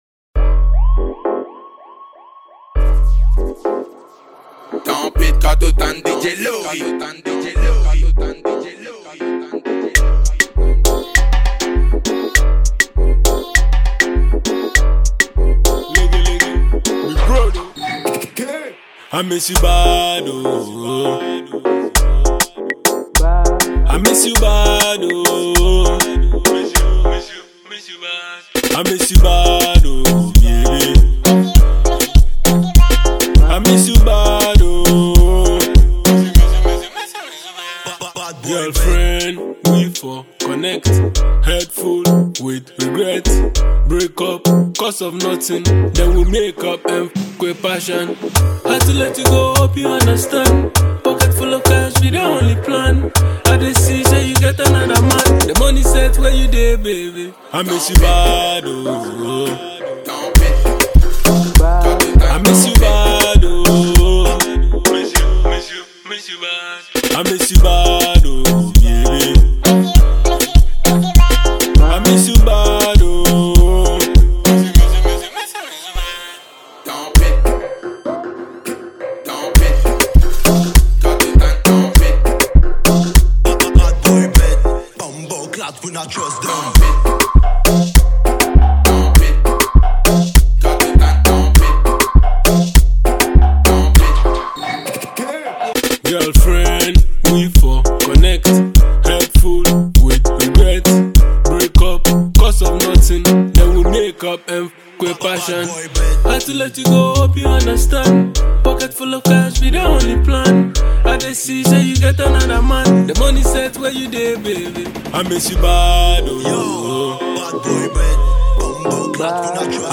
Enjoy this amazing studio track.